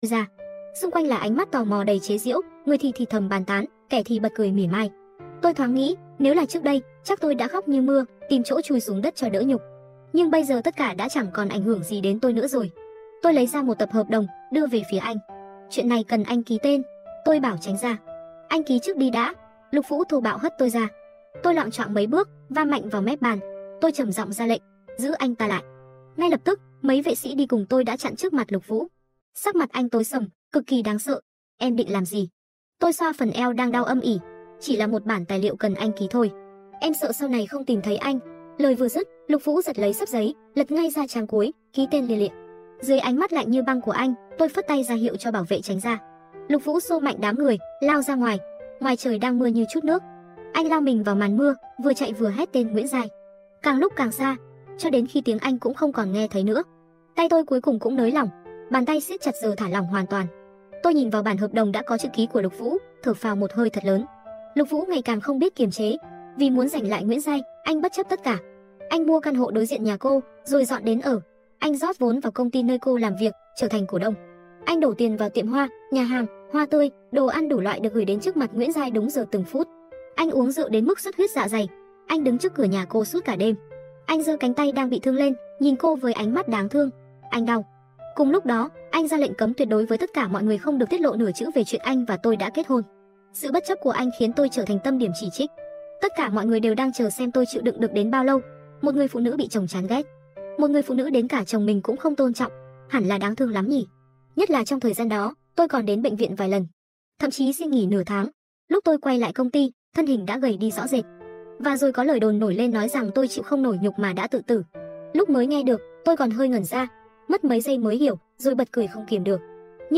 Truyện audio